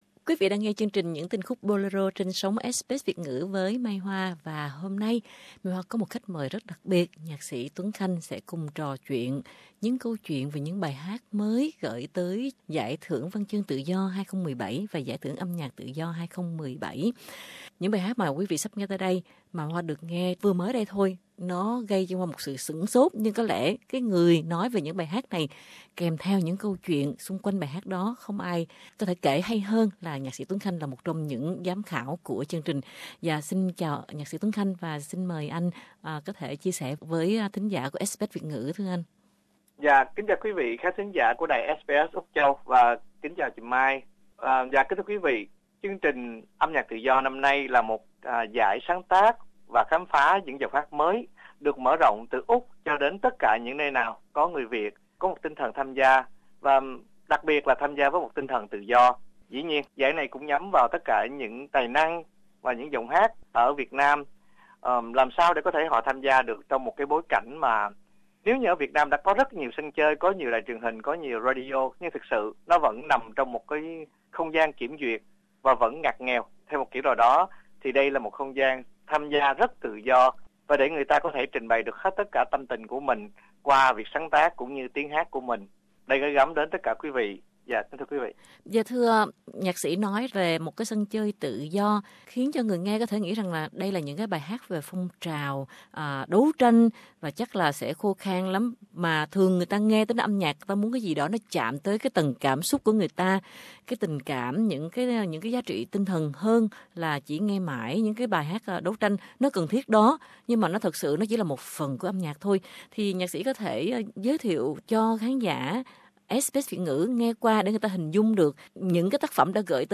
Ba tháng là 200 tác phẩm âm nhạc gởi tham dự Giải Thưởng Âm Nhạc Việt 2017 tại Sydney mà mỗi bài hát là một câu chuyện âm nhạc rất đặc biệt với những bí ẩn về tác giả đằng sau các ca khúc qua cuộc trò chuyện với Nhạc sĩ Tuấn Khanh - một trong ba vị giám khảo của giải.